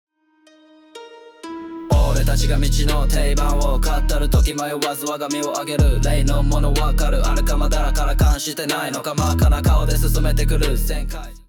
▼SUNOで作成した楽曲
Hiphop.mp3